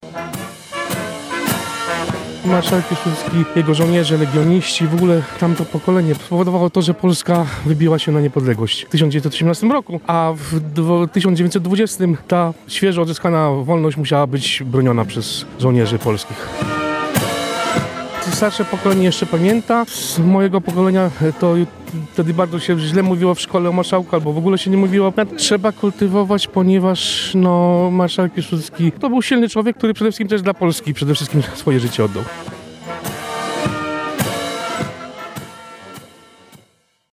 Mieszkańcy Lublina uczcili 157 urodziny marszałka Józefa Piłsudskiego. Obchody odbyły się przy pomniku marszałka Józefa Piłsudskiego na koniu – jego słynnej Kasztance.